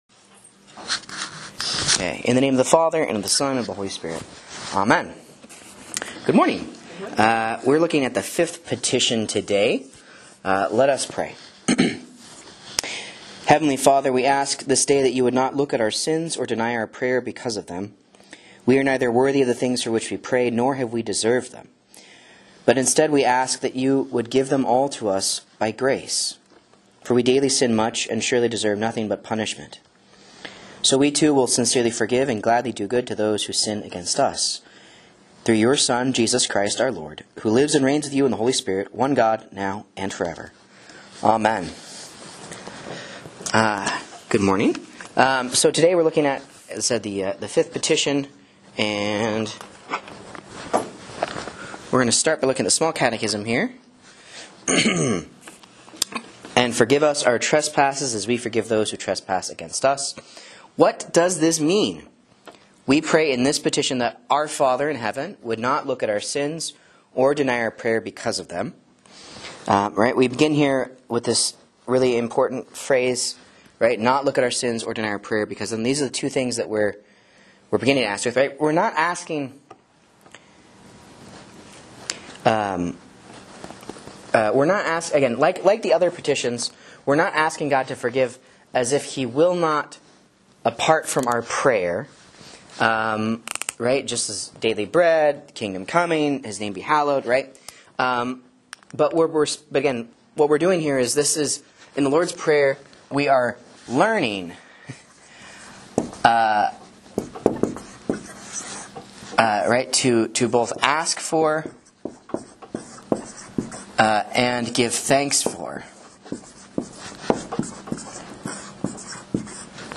Sermon and Bible Class Audio from Faith Lutheran Church, Rogue River, OR